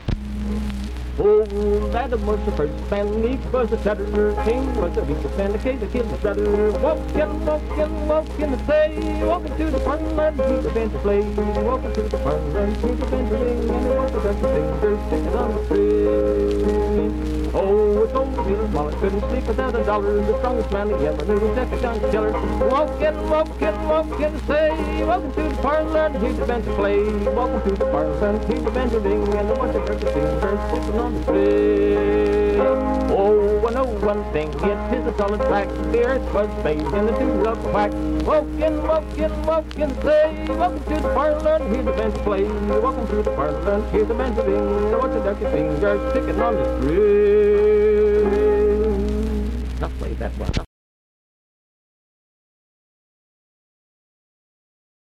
Vocal performance accompanied by banjo.
Miscellaneous--Musical
Banjo, Voice (sung)
Vienna (W. Va.), Wood County (W. Va.)